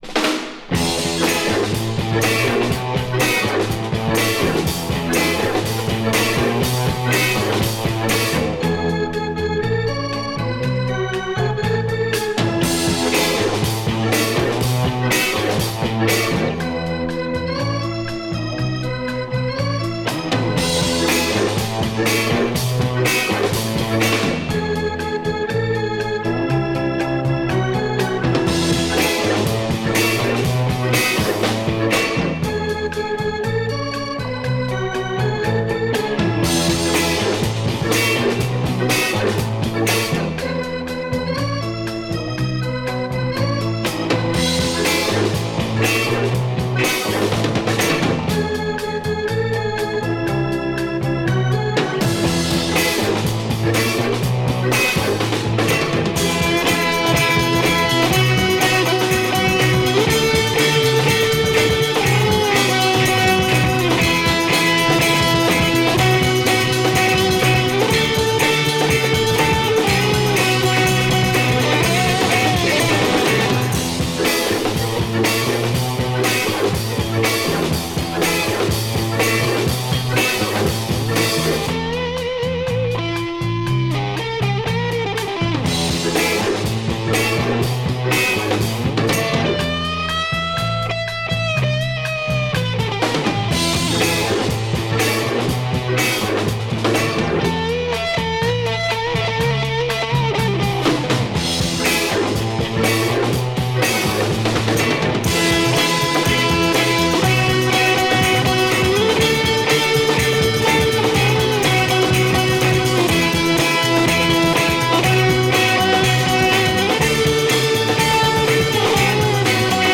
Killer Italian psychedelic library